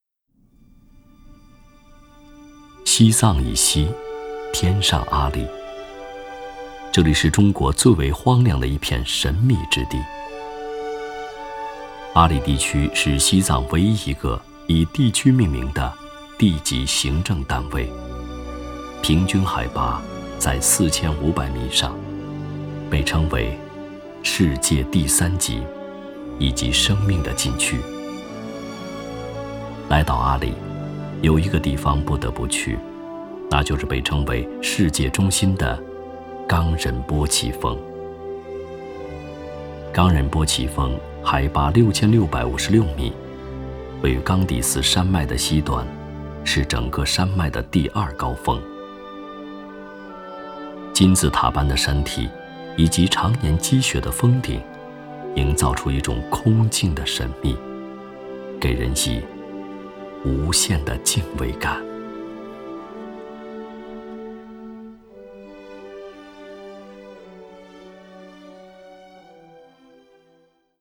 纪录配音--声音作品--海滨声音艺术学院